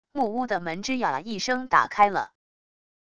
木屋的门吱呀一声打开了wav音频